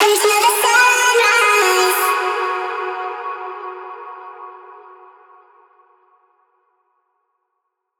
VR_vox_hit_sunrise_D#.wav